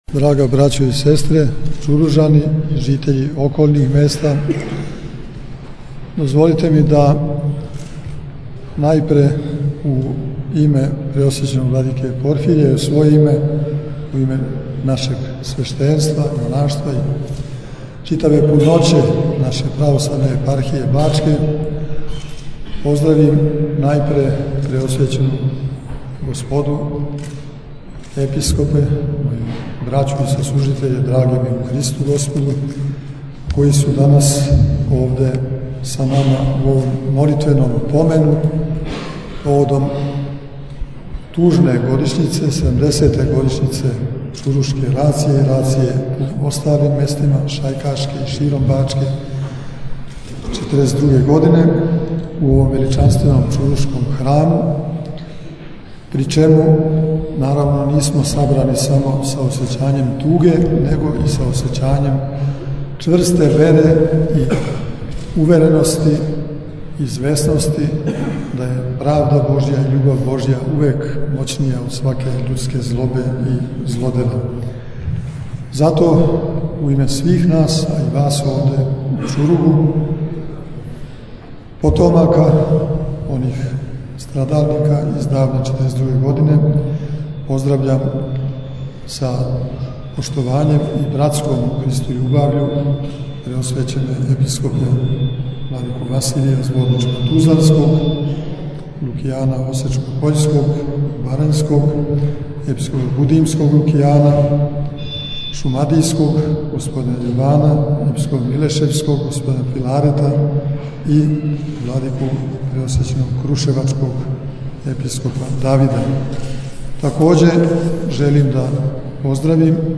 Новомученицима чурушким и свима који пострадaше у рацији 1942. године служен је, 4. јануара 2012. године, у цркви Вазнесења Господњег у Чуругу, парастос.
Беседе Епископа Иринеја и Епископа Порфирија: